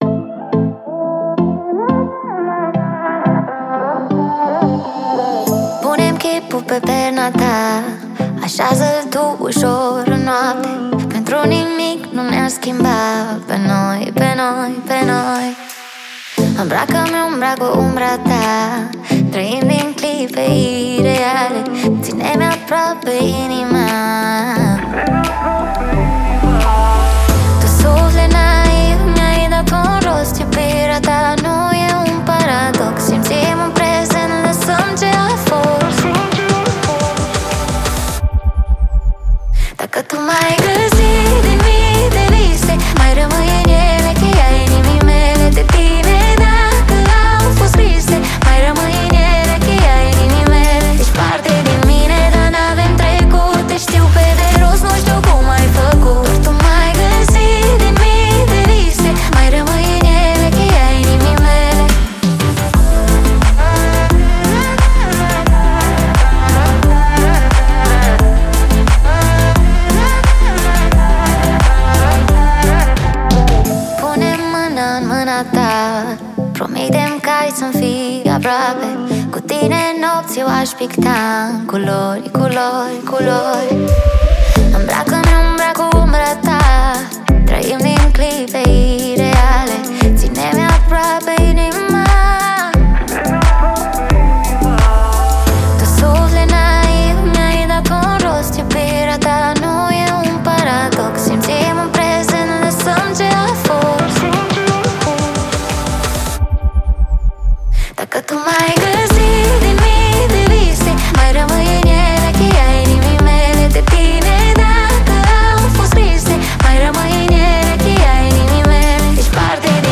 это зажигательная электронная композиция